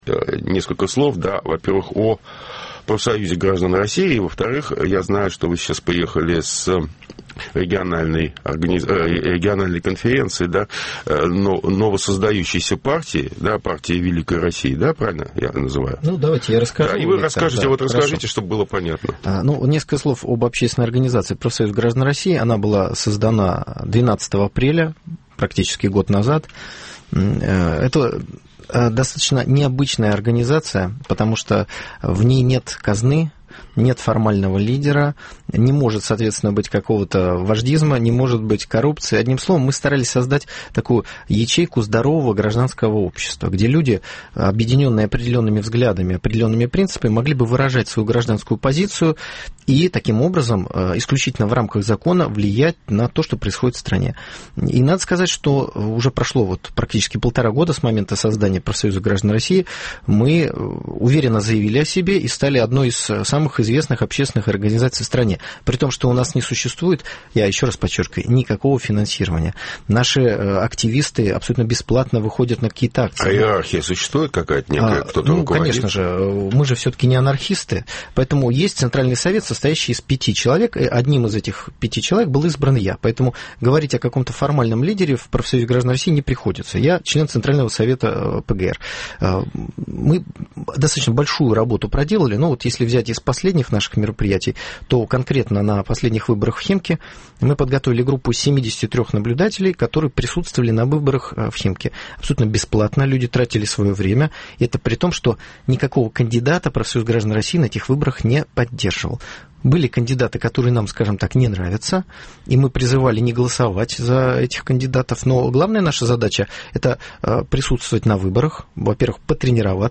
Круглый стол: Петербург Свободы